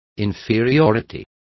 Also find out how inferioridad is pronounced correctly.